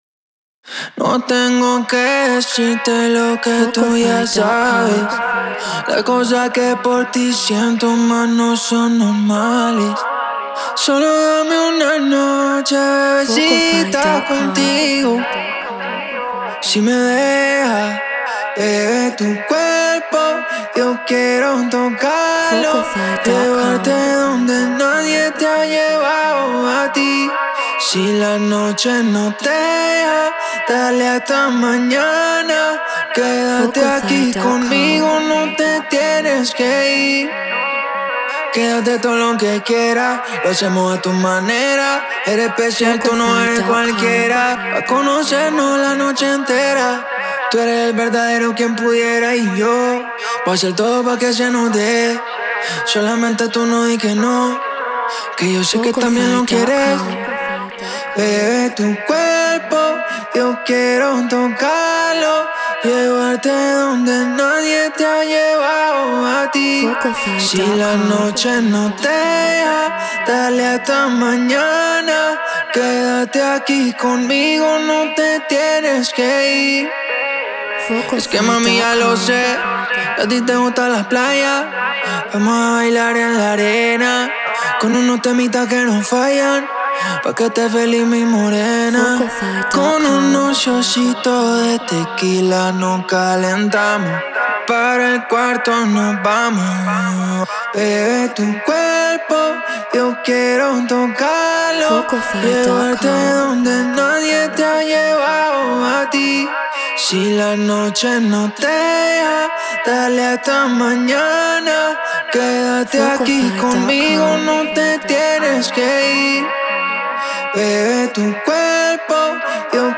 Reggaeton 97 BPM Bmaj